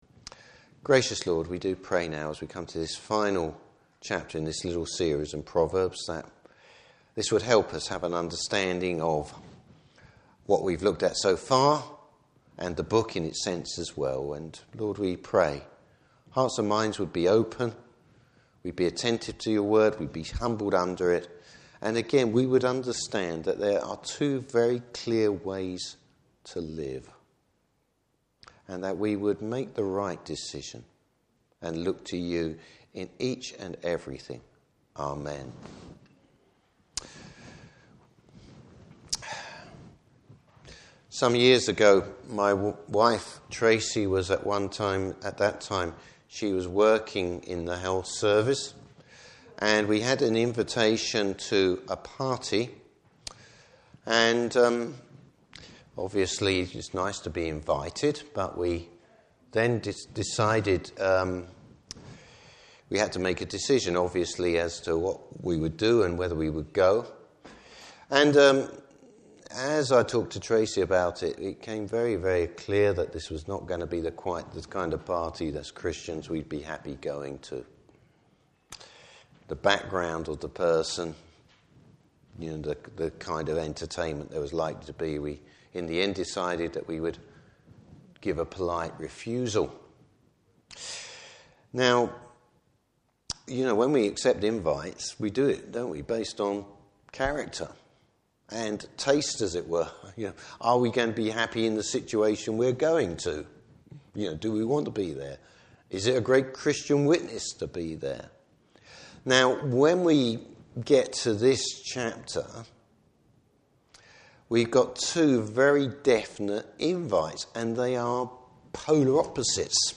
Service Type: Morning Service The choice we face in life.